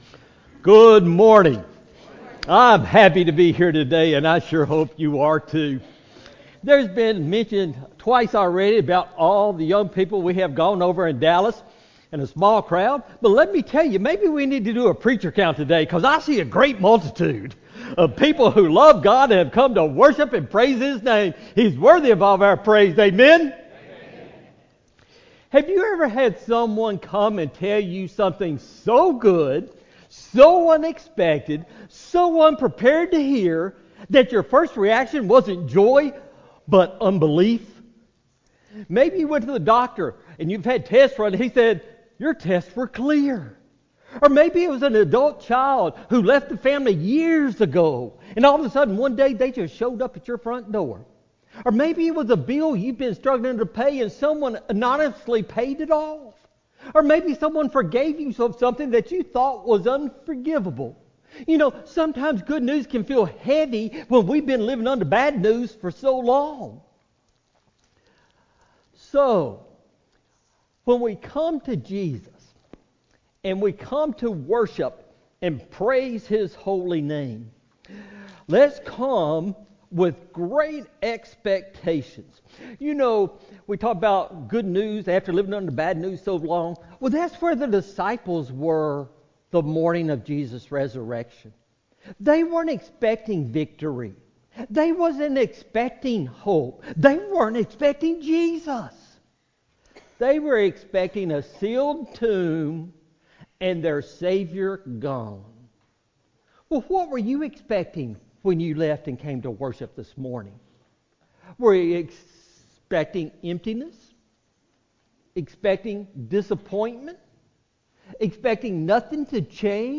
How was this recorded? Auditorium Audio